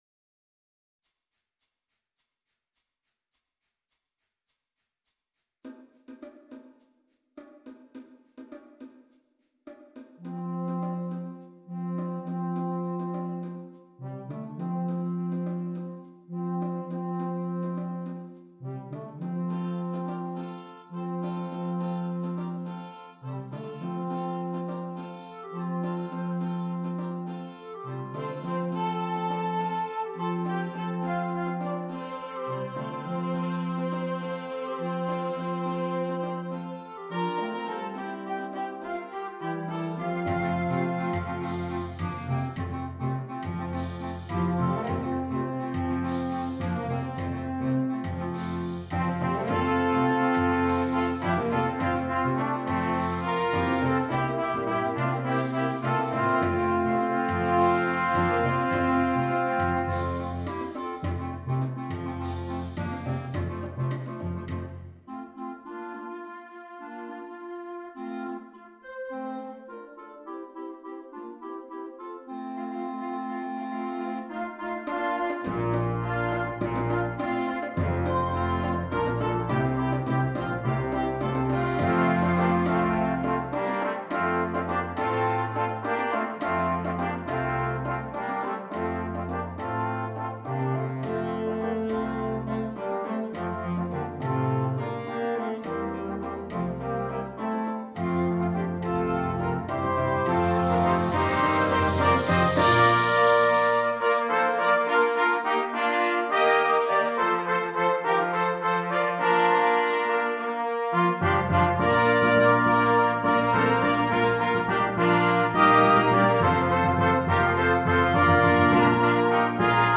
Concert Band and Optional Choir